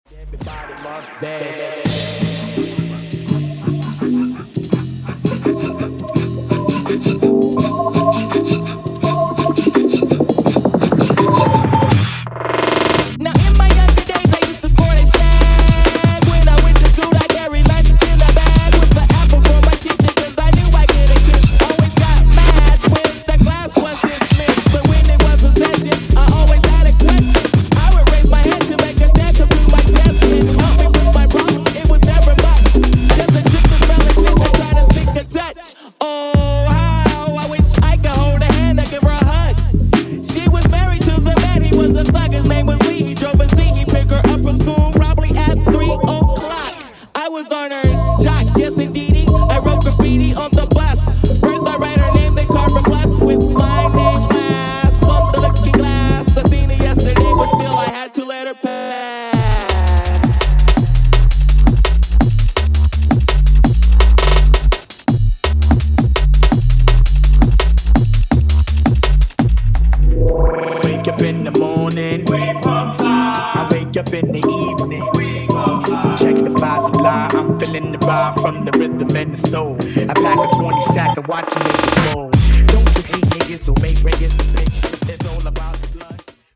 continuing with a classic hip hop/jungle mash up